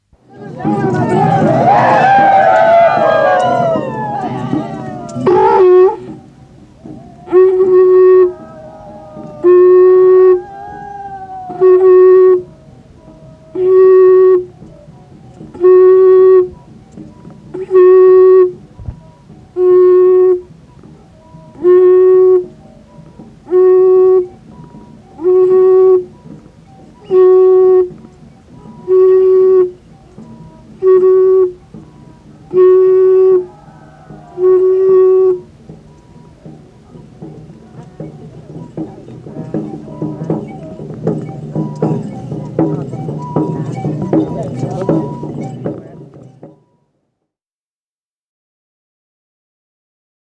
Música mapuche (Comunidad de Collinque, Lumaco)
Música tradicional